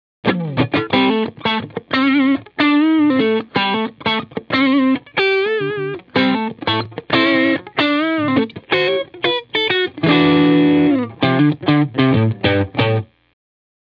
OD808-Overdrive-2.aif